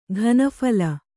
♪ ghana phala